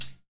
HiHat (Hey!).wav